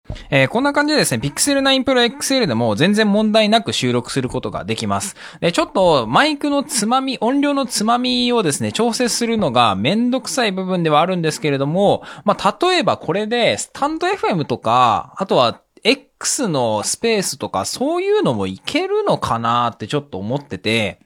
FIFINE Amplitank K688のUSB-Cとスマートフォンを直接USB接続する方法だ。
この方法でPixel 9 Pro XLのボイスレコーダーにて録音することができた。
fifine-k688-review-Audio-test-pixel.mp3